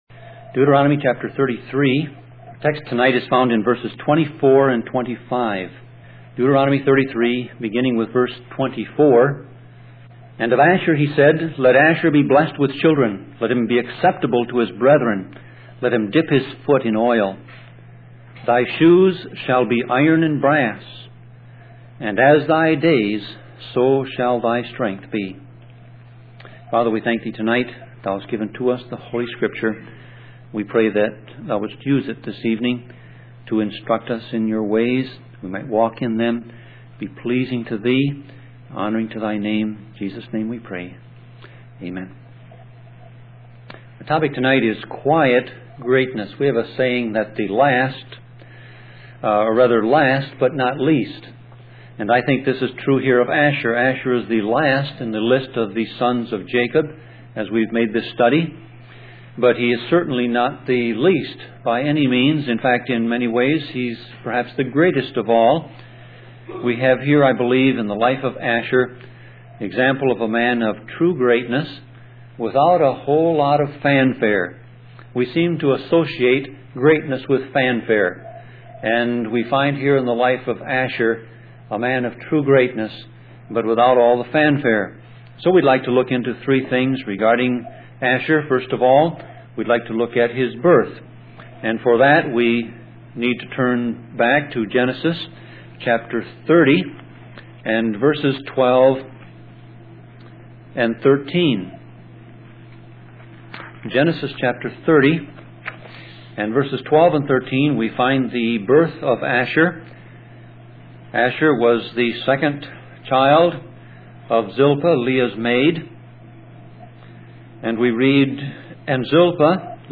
Sermon Audio Passage: Deuteronomy 33:24-25 Service Type